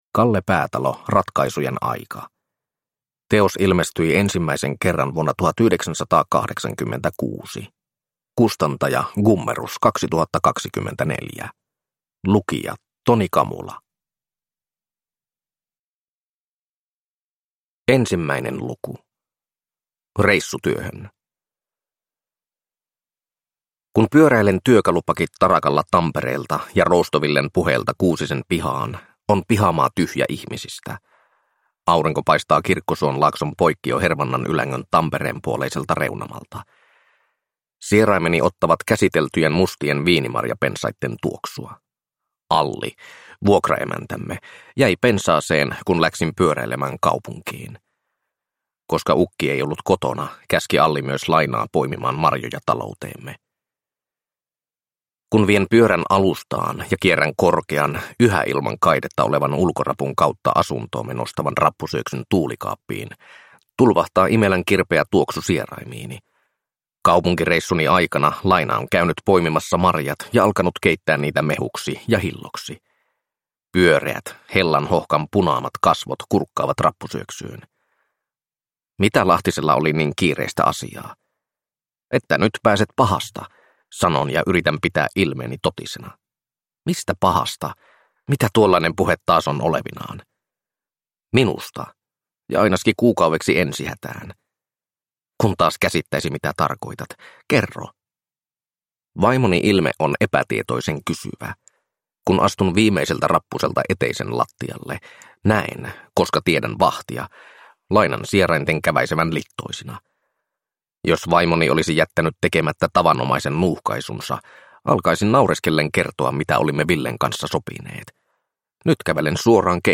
Ratkaisujen aika (ljudbok) av Kalle Päätalo